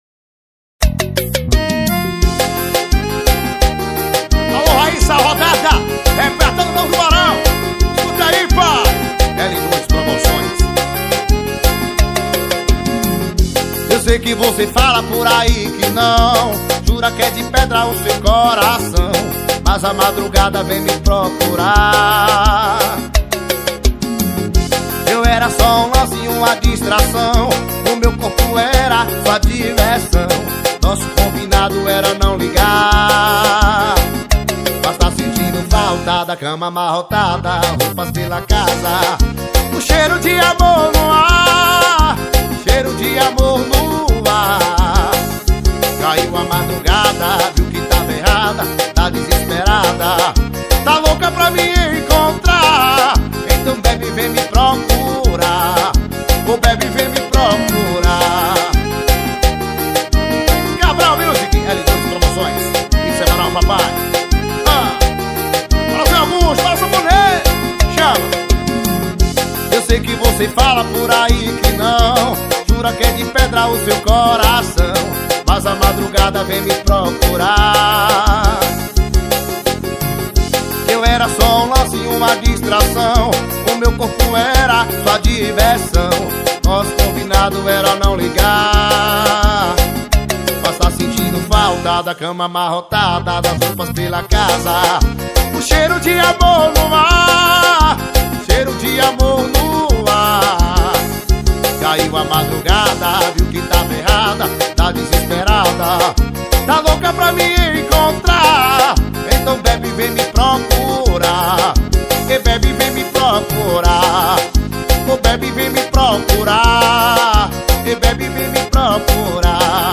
2024-10-09 04:36:12 Gênero: Forró Views